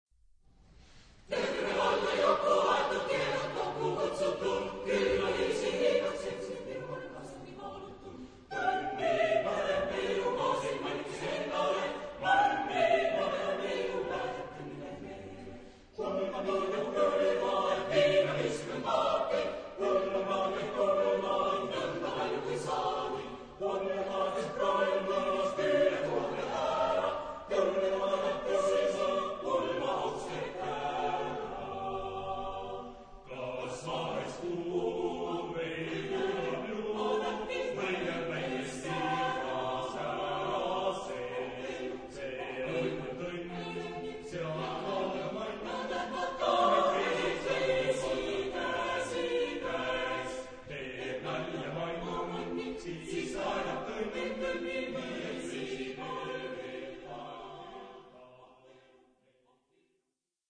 Epoque: 19th century
Genre-Style-Form: Polka ; Dance ; Folk music
Type of Choir: SATB  (4 mixed voices )
Tonality: G major